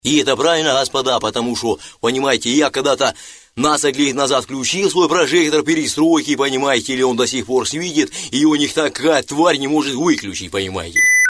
Пародия на голос Горбачева, Михаила Сергеевича.
Категория: пародии
Характеристика: Пародист